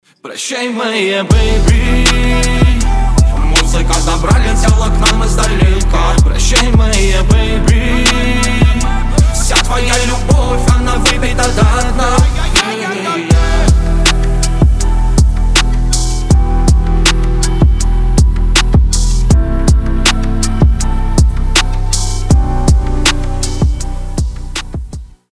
• Качество: 320, Stereo
Хип-хоп
грустные
растаманские